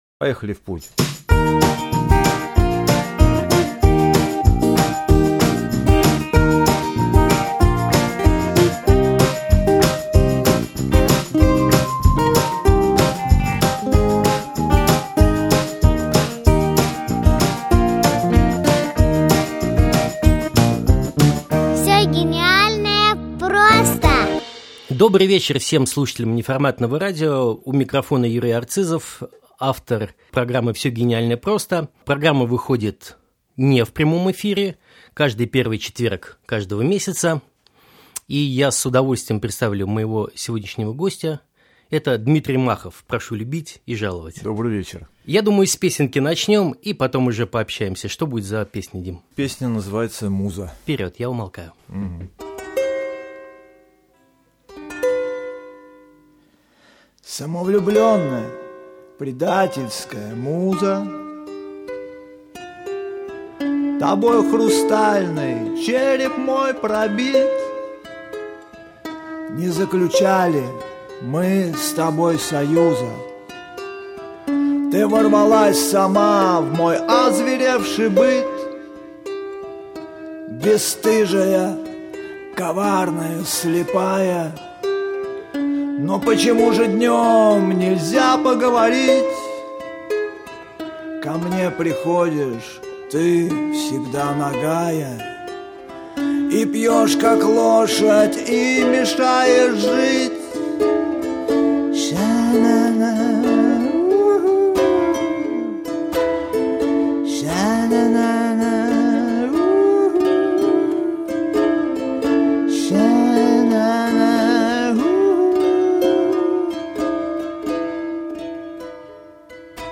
укулеле